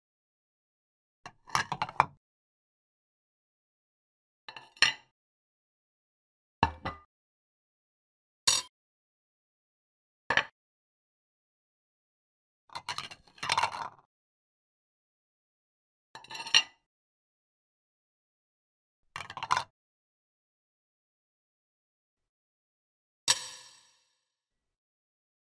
دانلود صدای بشقاب 2 از ساعد نیوز با لینک مستقیم و کیفیت بالا
جلوه های صوتی